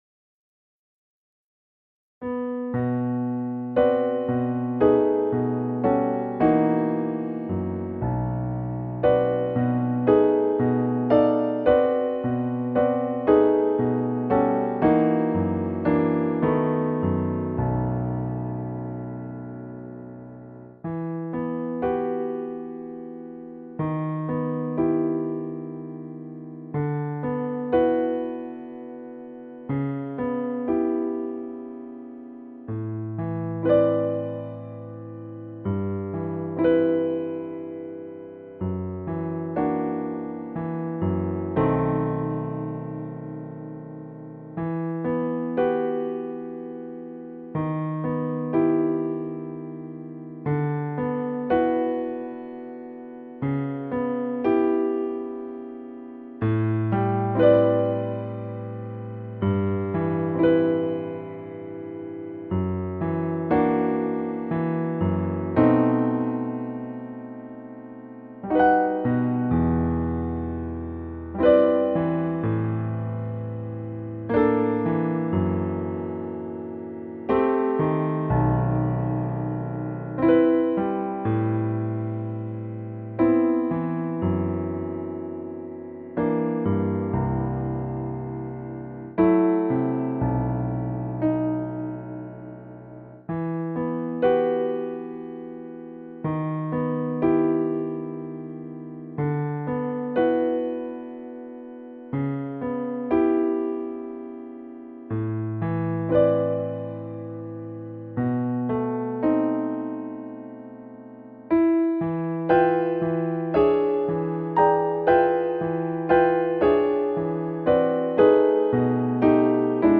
Siesta Piano